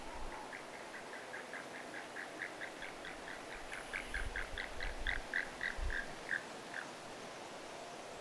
merikotka
merikotka.mp3